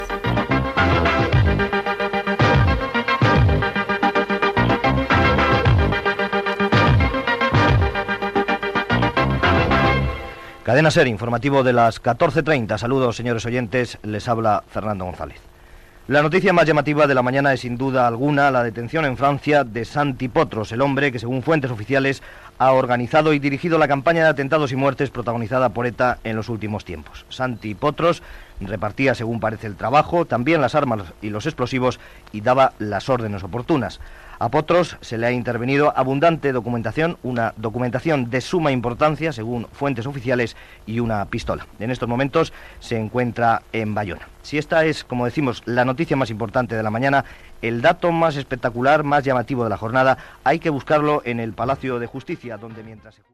Careta del programa, presentació, detenció a França de Santi Potros de la banda ETA
Informatiu